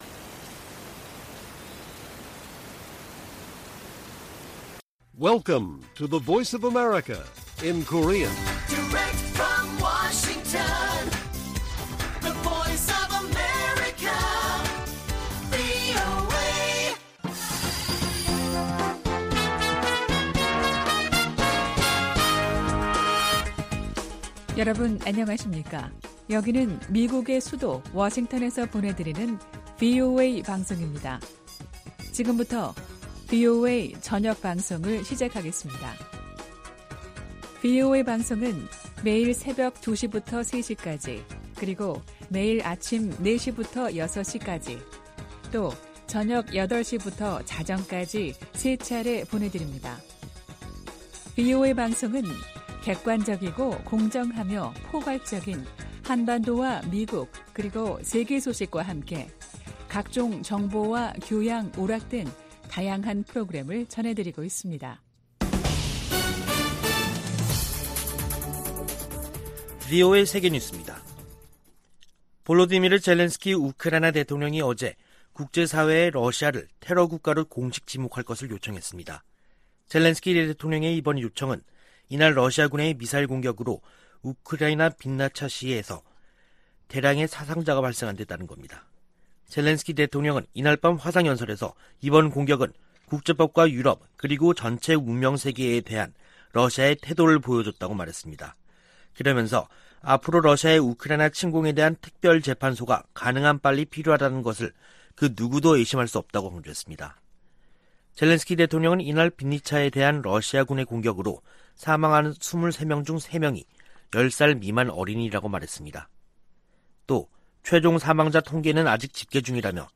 VOA 한국어 간판 뉴스 프로그램 '뉴스 투데이', 2022년 7월 15일 1부 방송입니다. 재닛 옐런 미 재무장관은 오는 19일 방한에서 경제관계를 강화하고 대북 제재를 이행 문제 등을 논의할 것이라고 밝혔습니다. 미한 공군의 F-35A 스텔스 연합비행훈련은 북한 도발에 전략자산으로 대응할 것이라는 경고를 보내는 것이라고 미국 군사전문가들은 평가했습니다. 마크 에스퍼 전 미 국방장관은 '쿼드' 확대의 필요성을 강조하며 적합한 후보국으로 한국을 꼽았습니다.